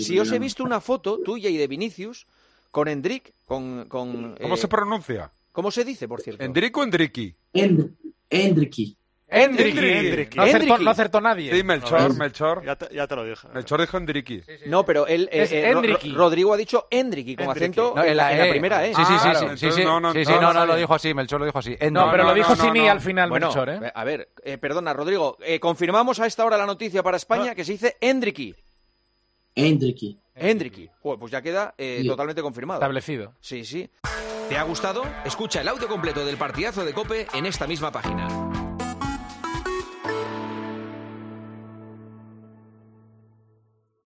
El jugador brasileño del Real Madrid Rodrygo Goes se pasó anoche en directo por los micrófonos de El Partidazo de COPE donde analizó la actualidad blanca y además se sinceró sobre su posición favorita en el Real Madrid.
Juanma Castaño entrevista a Rodrygo Goes en El Partidazo de COPE